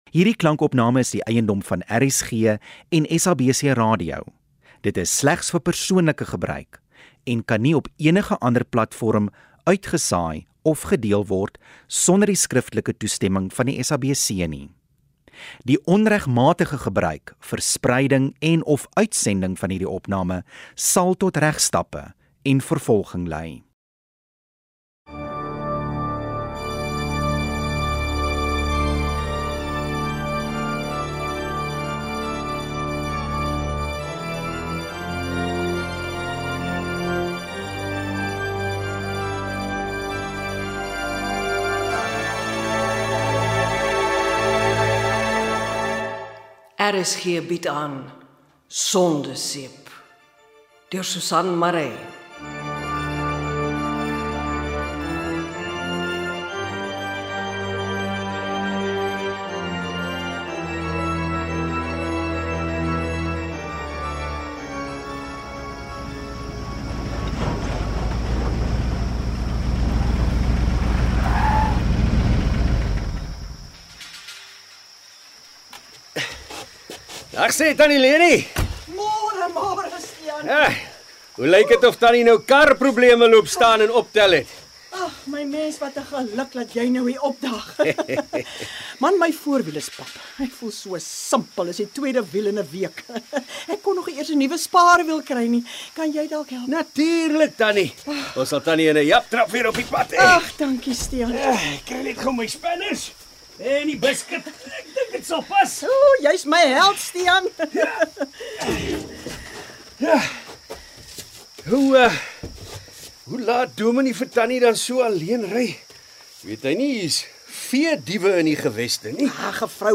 radiodrama